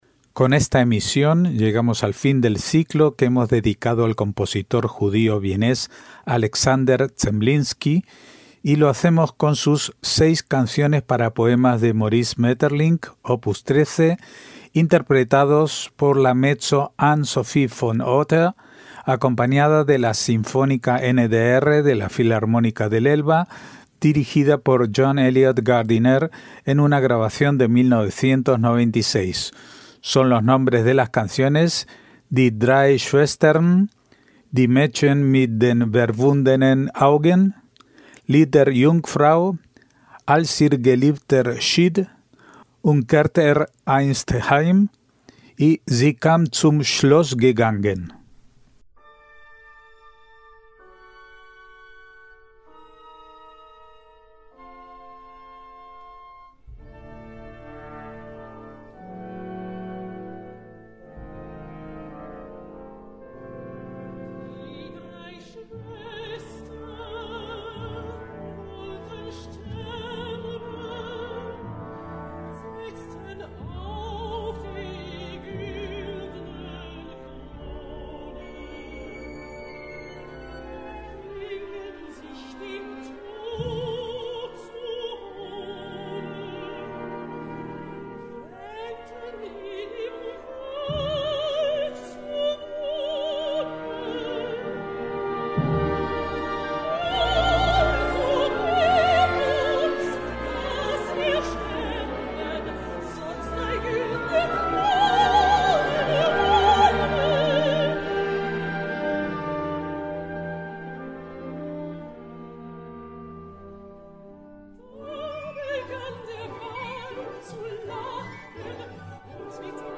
mezzo